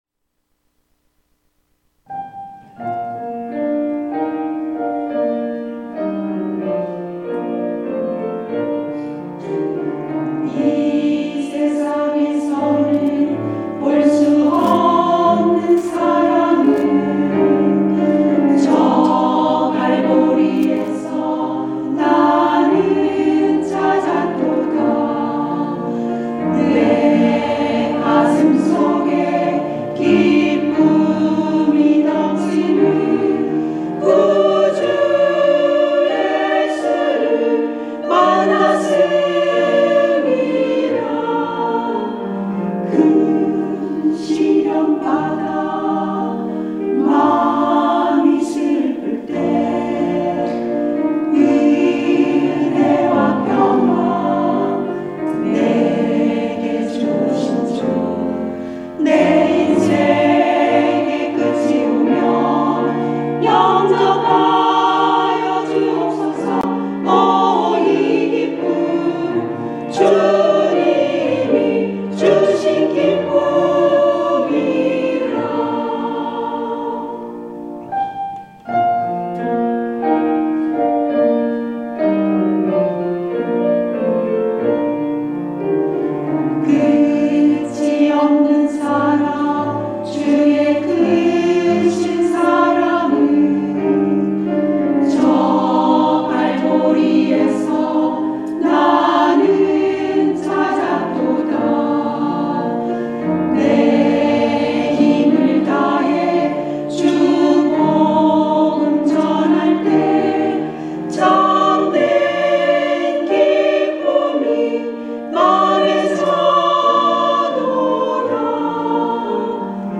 특송과 특주 - 주를 섬기는 기쁨, 감사해
권사 합창단